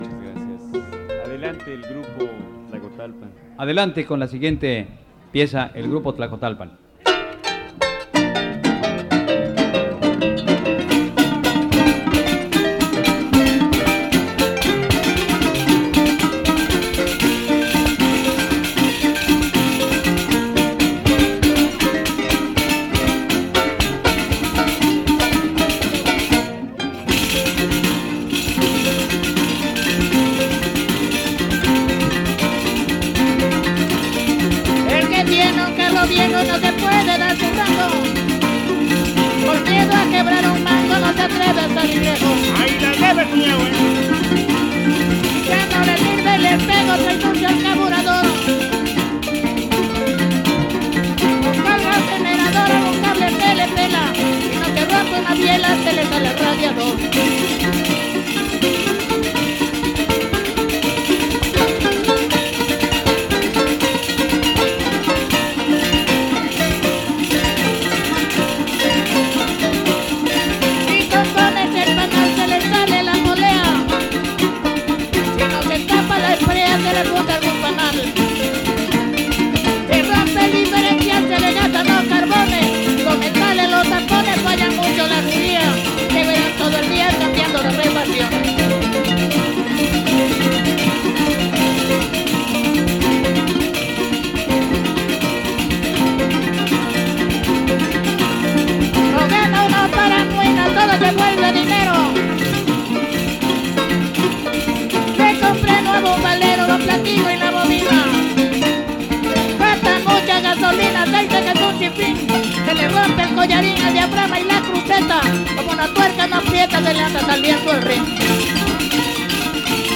• Tlacotalpan (Grupo musical)
Noveno Encuentro de jaraneros